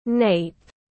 Cái gáy tiếng anh gọi là nape, phiên âm tiếng anh đọc là /neɪp/.
Nape /neɪp/